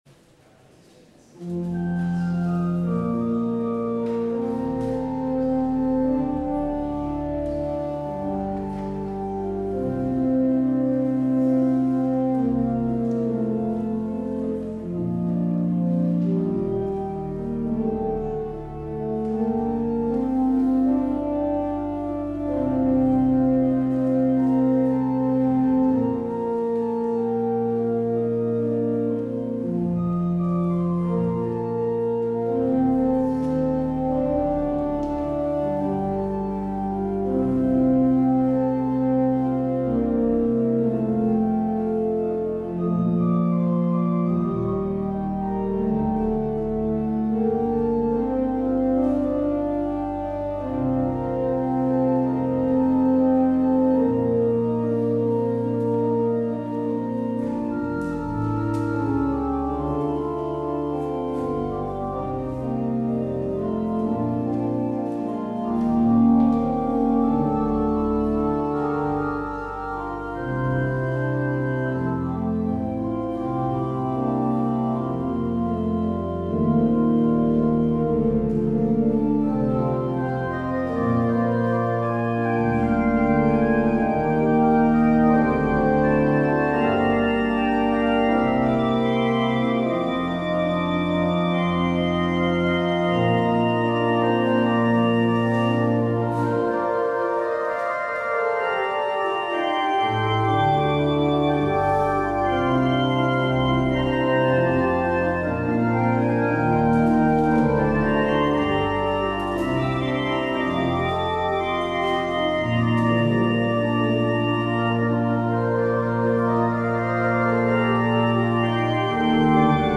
 Luister deze kerkdienst hier terug: Alle-Dag-Kerk 17 januari 2023 Alle-Dag-Kerk https